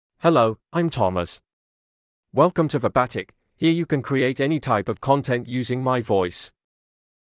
Thomas — Male English (United Kingdom) AI Voice | TTS, Voice Cloning & Video | Verbatik AI
MaleEnglish (United Kingdom)
Thomas is a male AI voice for English (United Kingdom).
Voice sample
Thomas delivers clear pronunciation with authentic United Kingdom English intonation, making your content sound professionally produced.